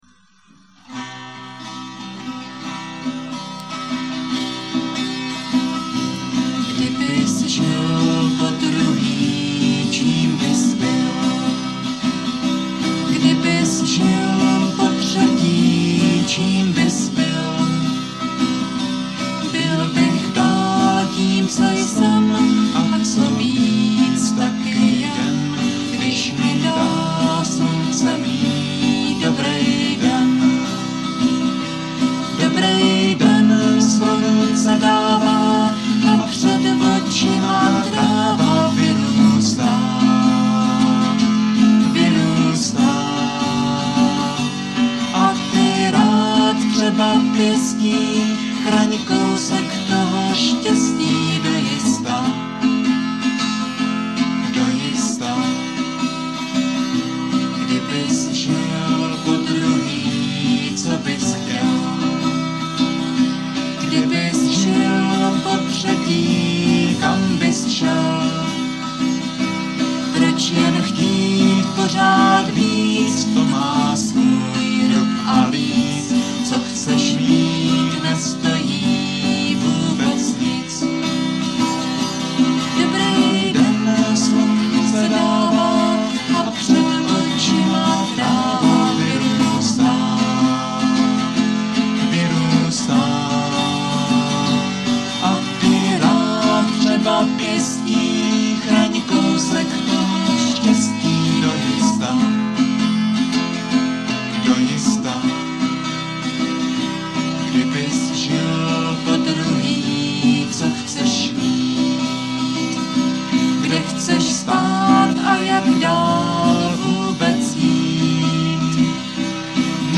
zpívejte na záznamy mp3 v podání 1 kytary a dvou hlasů